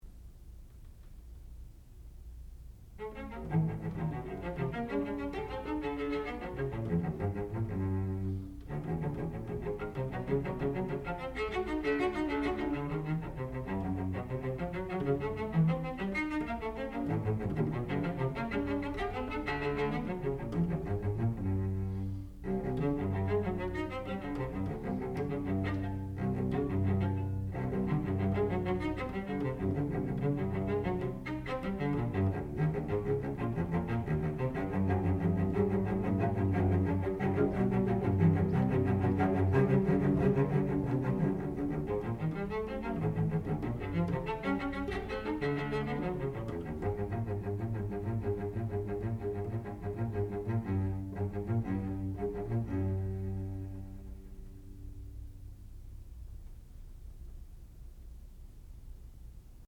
Sonata, op. 25, no. 3 for violoncello solo
classical music
Advanced recital